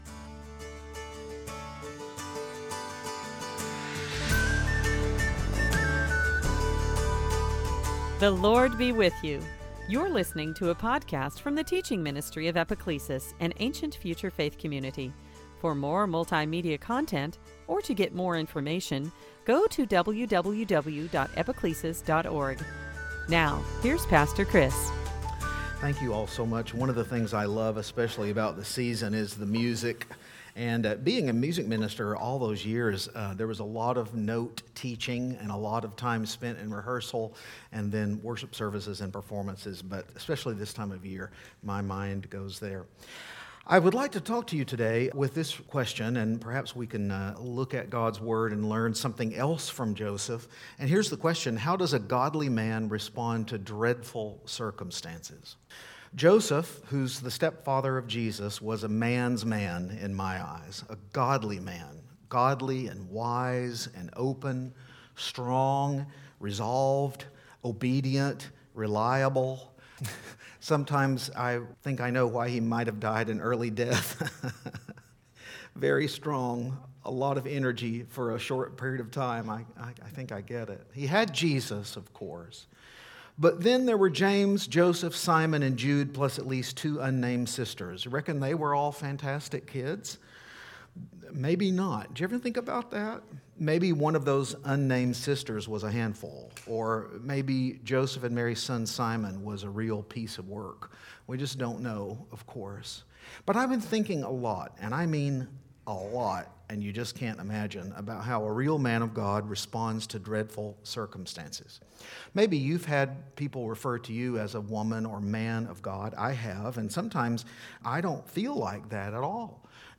Matthew 2:13-23 Service Type: Christmastide How does a godly man respond to dreadful circumstances?